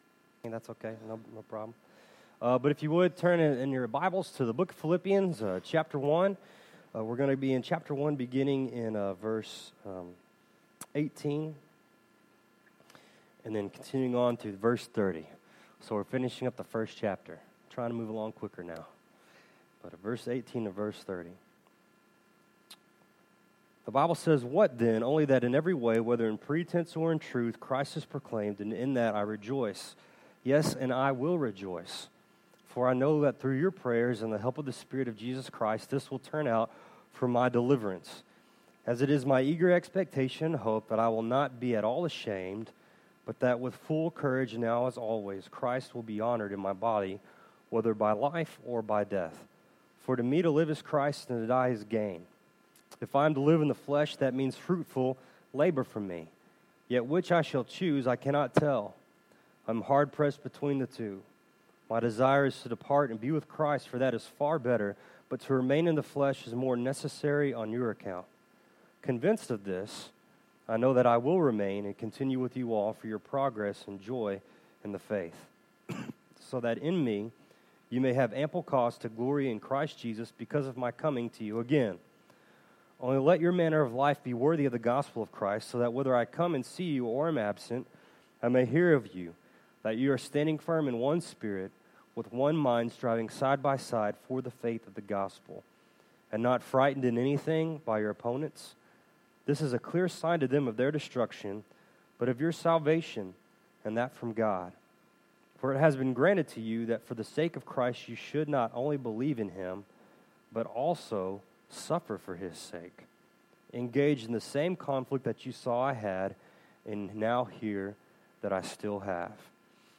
Passage: Philippians 1:18-30 Service Type: Sunday Morning Bible Text